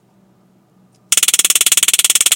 泰瑟枪/高压电/电击枪/电击 " 泰瑟枪/高压电放电
Tag: ZAP 血浆 火花 高压 臭氧 泰瑟 冲击 电动